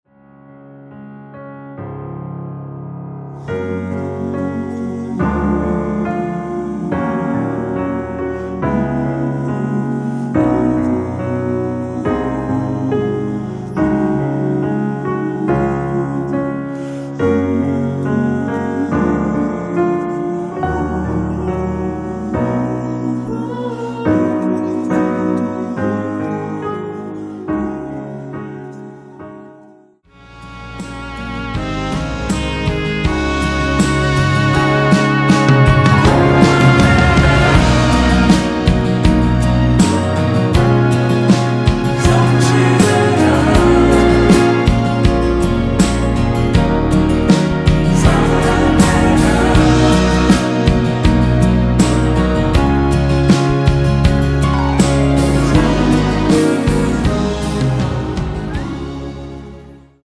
노래가 바로 시작하는곡이라 카운트 넣어 놓았으며
그리고 엔딩이 너무 길고 페이드 아웃이라 라랄라 반복 2번으로 하고 엔딩을 만들었습니다.
원키에서(-3)내린 코러스 포함된 MR입니다.(미리듣기 확인)
Gb
앞부분30초, 뒷부분30초씩 편집해서 올려 드리고 있습니다.